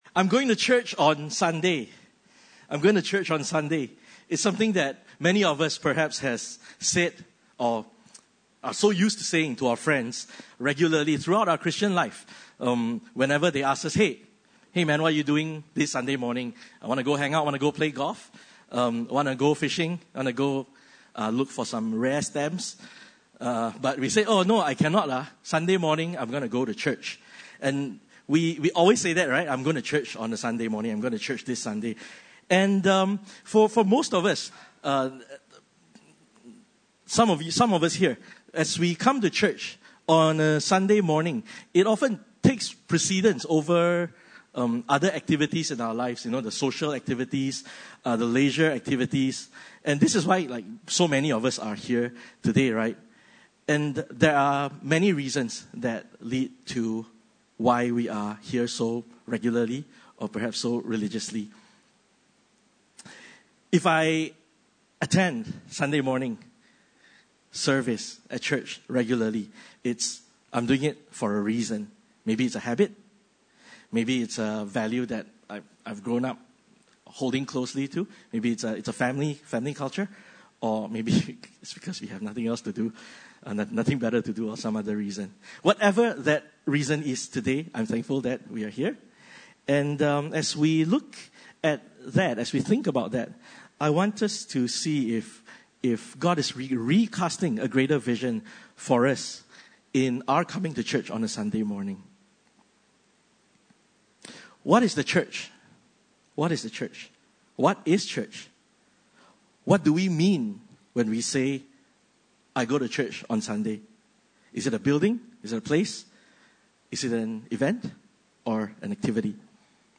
Passage: 1 Corinthians 12:12-27 Service Type: Sunday Service